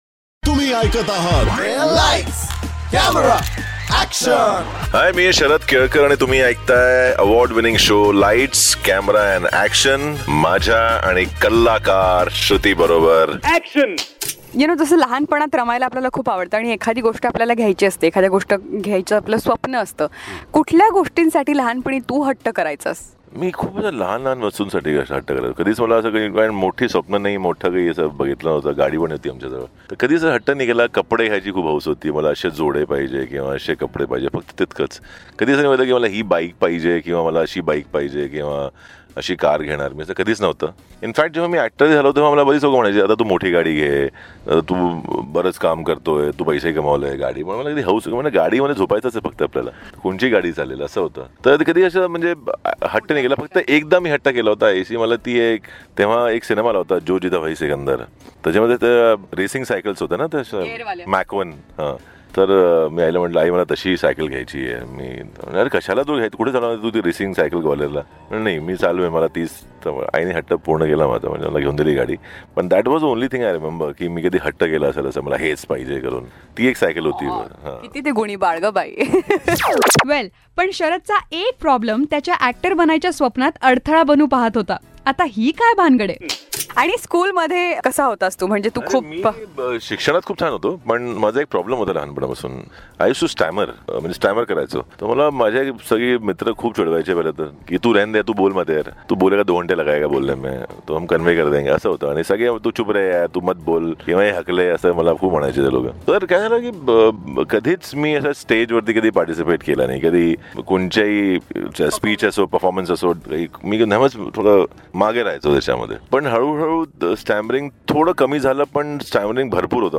CONVERSATION
a sexy man with equally sexy voice.